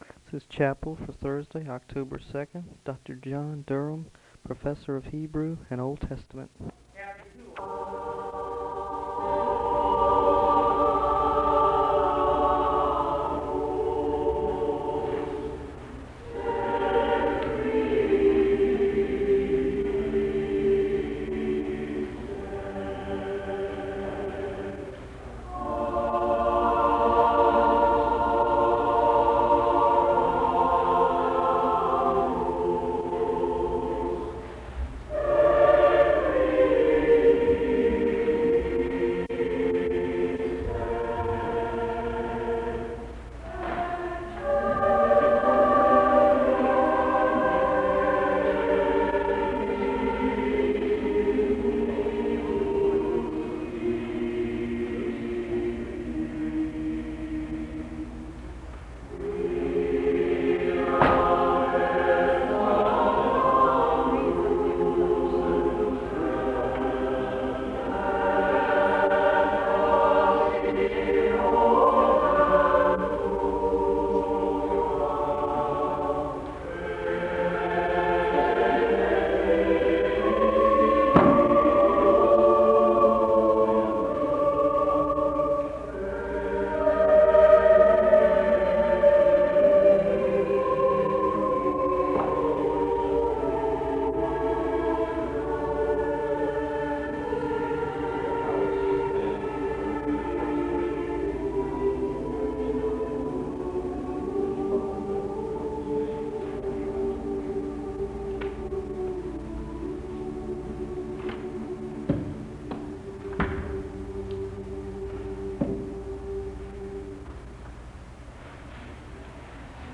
The service begins with a song from the choir and organ music (0:00-7:15). There is a call to worship (7:16-7:48). The choir sings a song of worship (7:49-9:49). There are Scripture readings from Psalms 148 and Philippians 2 (9:50-13:19).